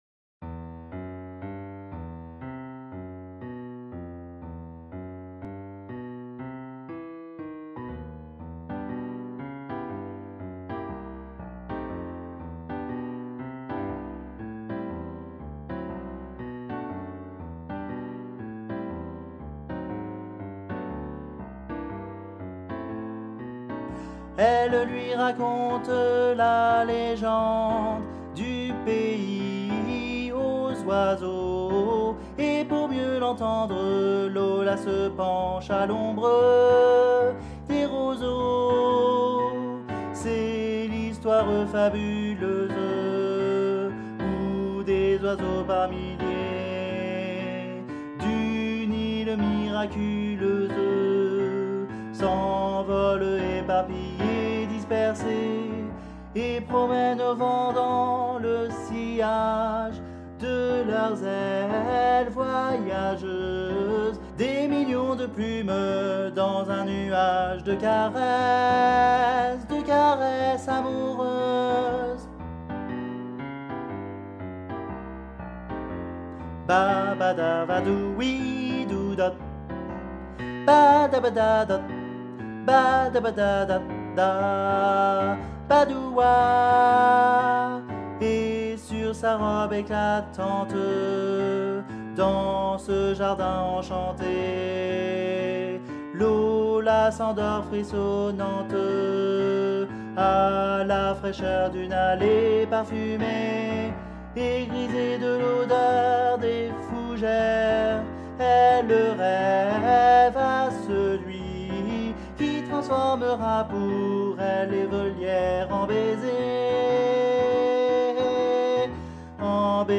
MP3 versions chantées
Guide Voix Barytons